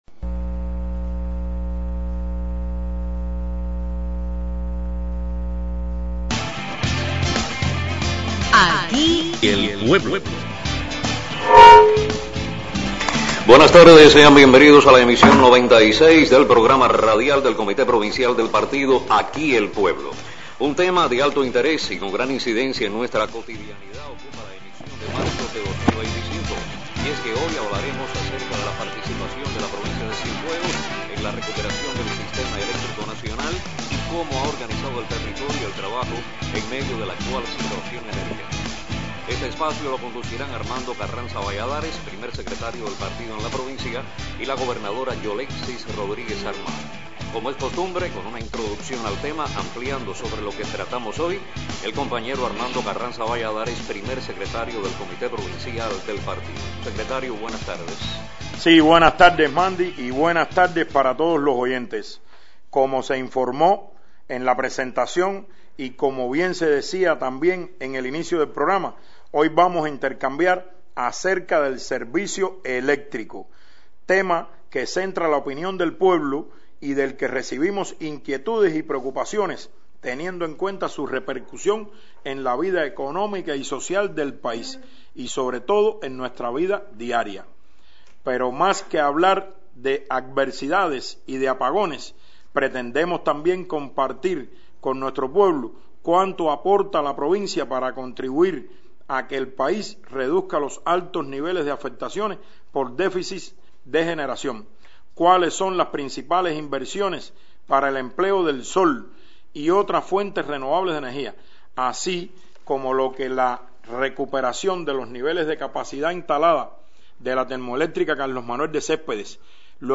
Las inversiones que ejecuta la provincia de Cienfuegos para disminuir los cortes eléctricos y las acciones de la Empresa Eléctrica de Cienfuegos para enfrentar el déficit de capacidad de generación eléctrica fueron temas abordados en la más reciente emisión del programa Aquí el pueblo, transmitido por Radio Ciudad del Mar, con la conducción de las máximas autoridades del Partido Comunista de Cuba y el Gobierno en la provincia.